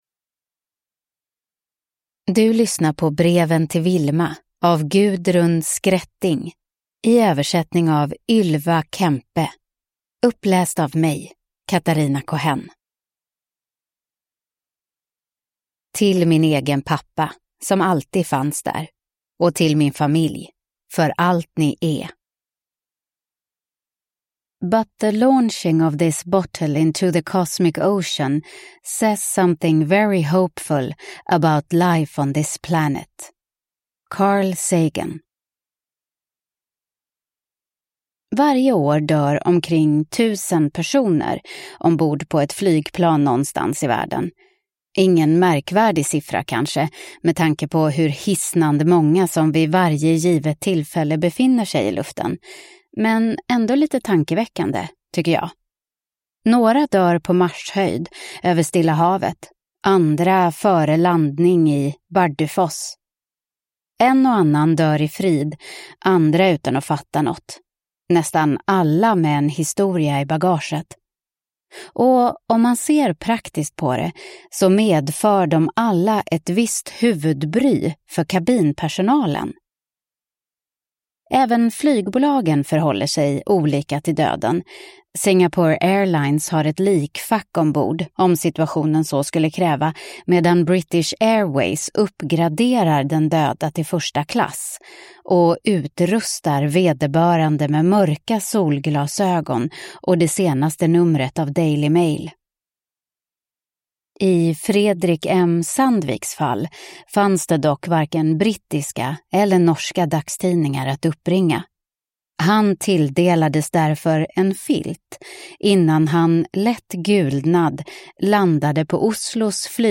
Breven till Vilma – Ljudbok
• Ljudbok